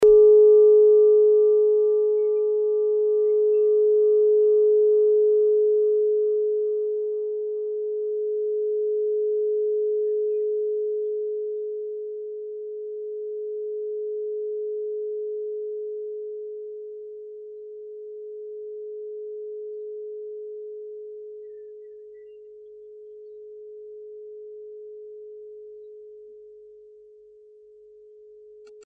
Klangschale Nepal Nr.19
Klangschale-Gewicht: 910g
(Ermittelt mit dem Filzklöppel)
klangschale-nepal-19.mp3